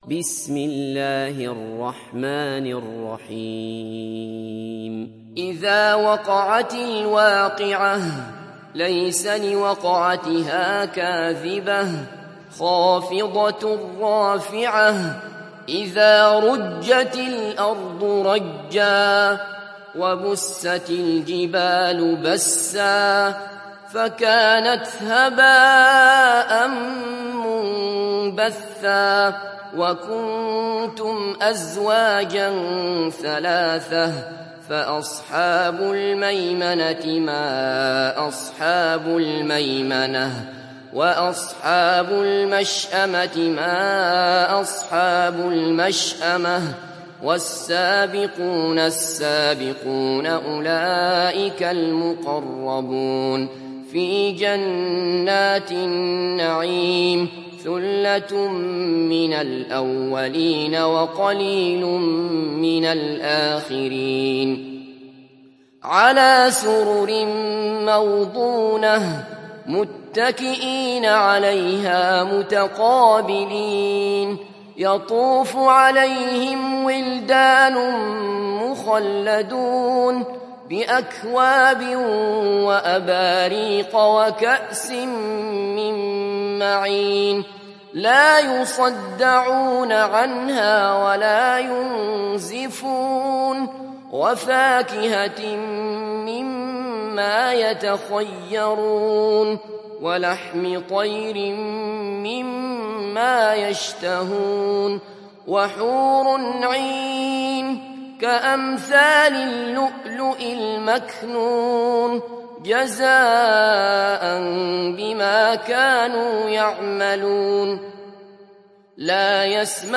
تحميل سورة الواقعة mp3 بصوت عبد الله بصفر برواية حفص عن عاصم, تحميل استماع القرآن الكريم على الجوال mp3 كاملا بروابط مباشرة وسريعة